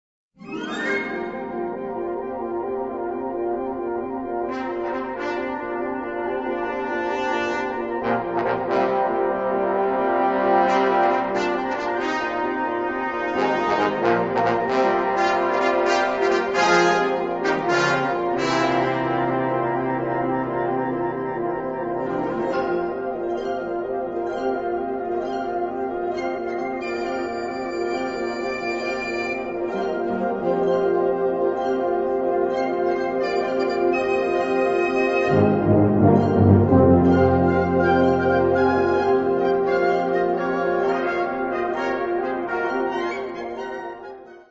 Gattung: Zeitgenössische Originalmusik
Besetzung: Blasorchester